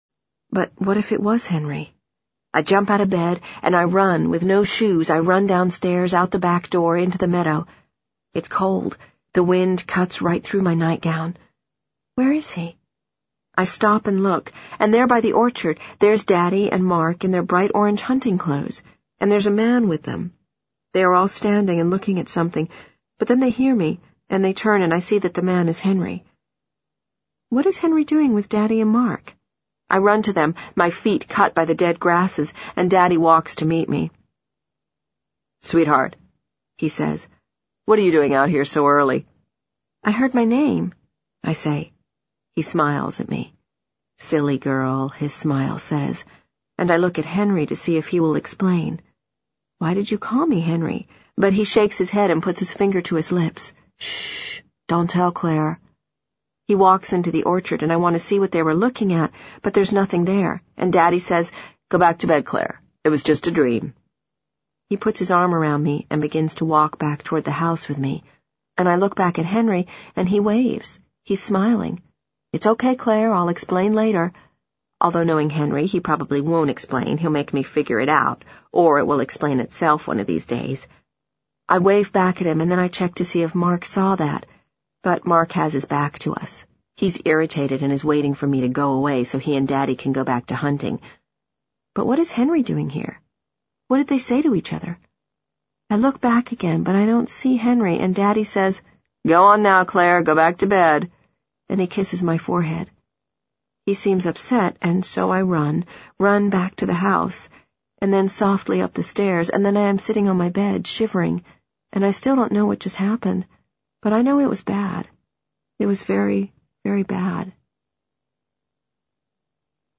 在线英语听力室【时间旅行者的妻子】69的听力文件下载,时间旅行者的妻子—双语有声读物—英语听力—听力教程—在线英语听力室